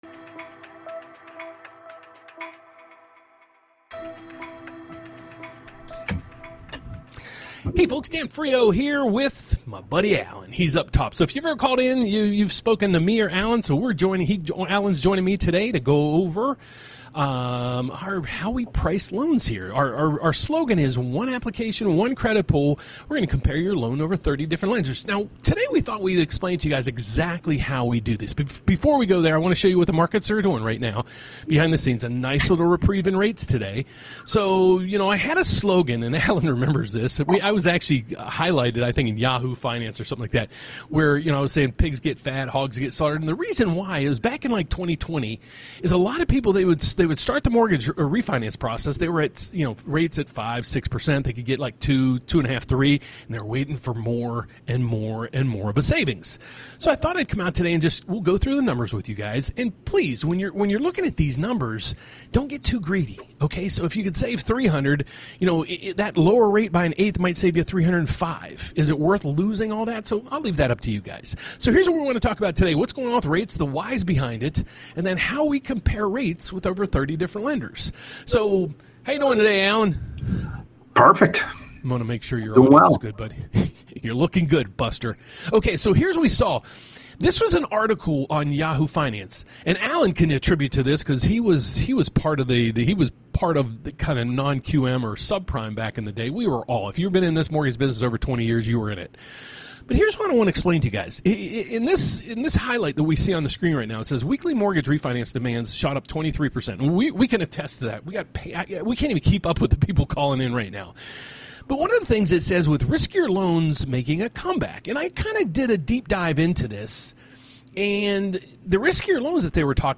Live Tutorial: Find the Lowest Mortgage Rate Among 30+ Lenders